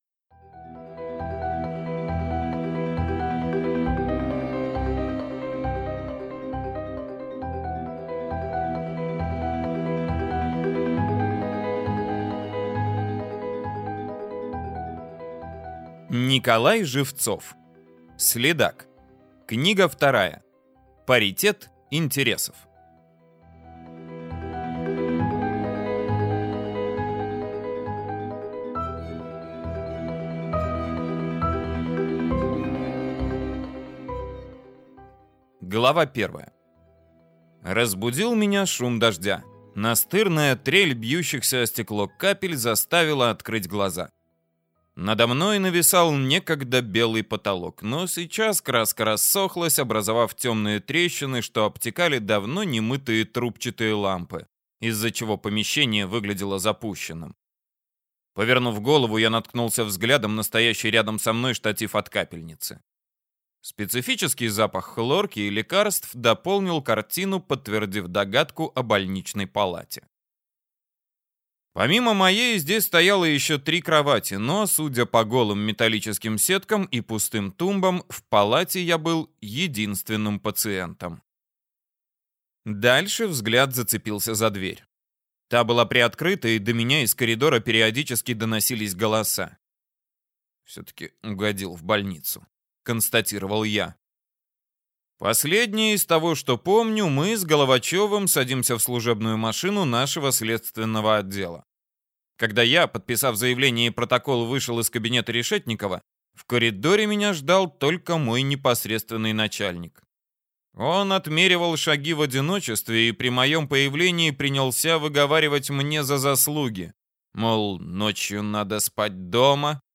Аудиокнига Следак. Паритет интересов | Библиотека аудиокниг